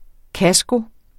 Udtale [ ˈkasgo ]